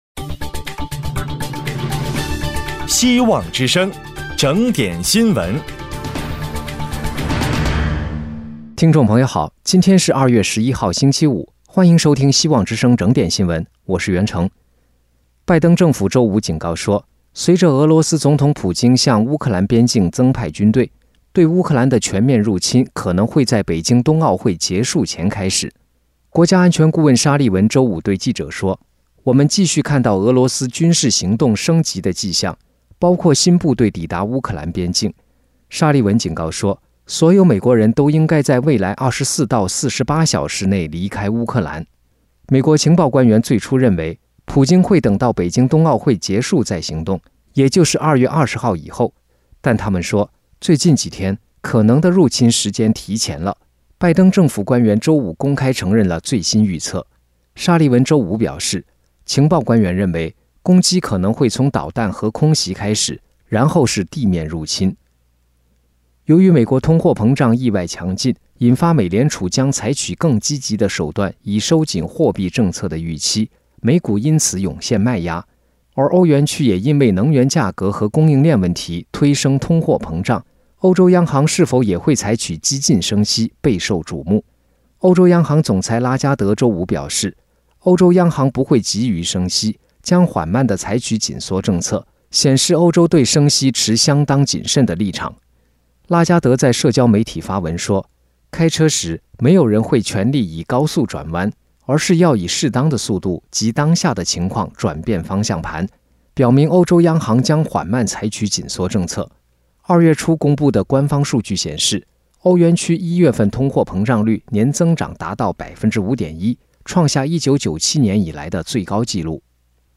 【希望之聲2022年2月12日】（配音：